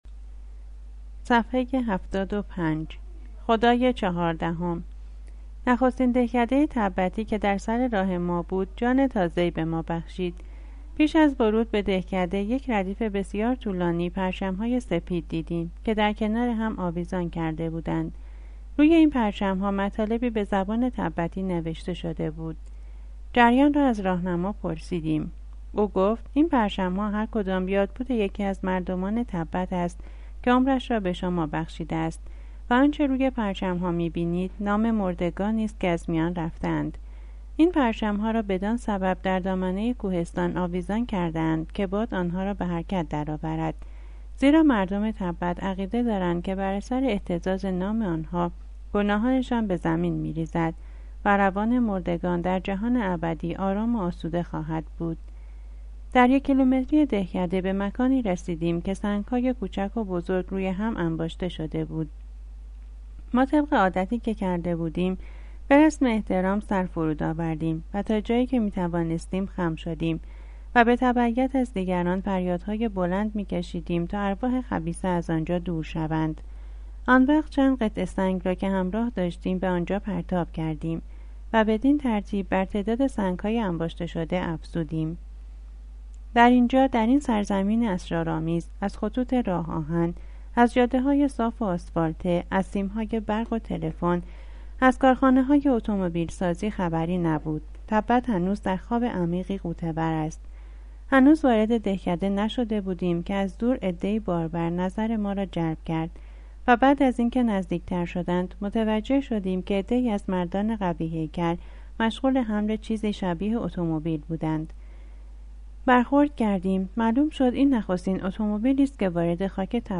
کتاب صوتی سفرنامه برادران امیدوار(9)